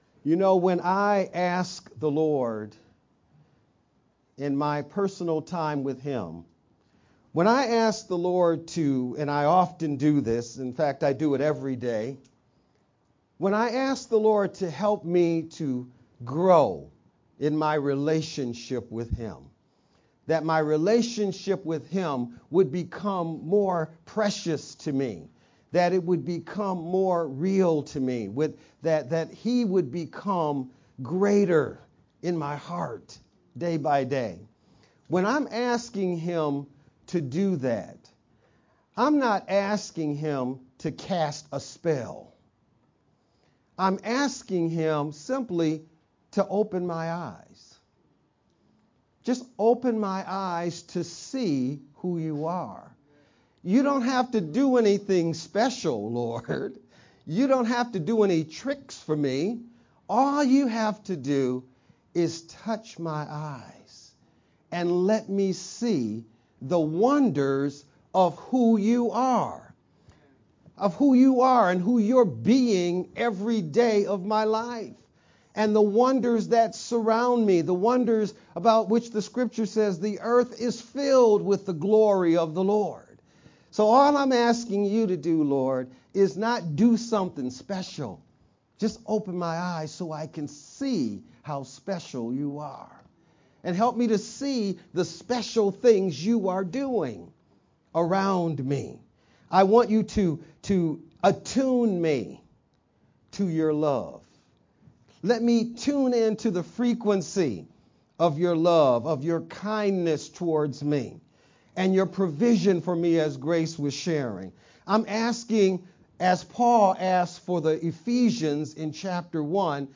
VBCC-Sermon-edited-9-17-sermon-only-_Converted-CD.mp3